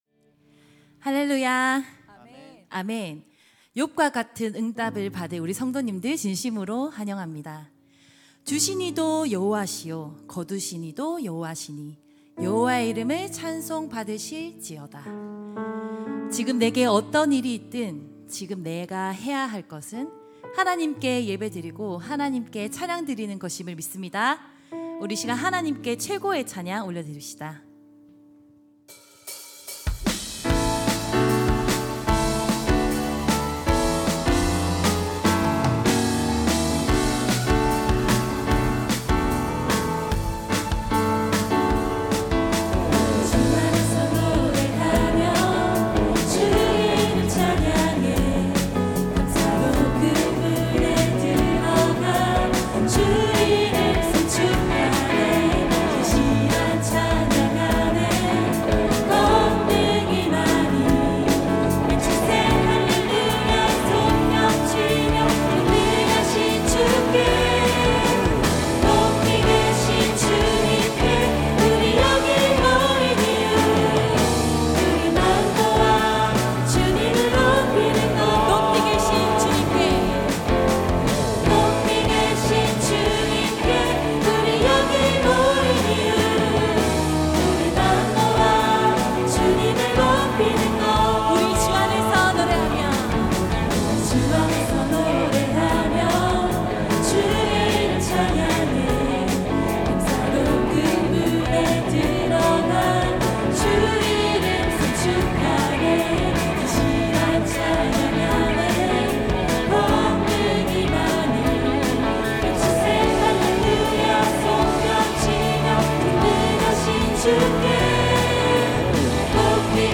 온세대예배 찬양대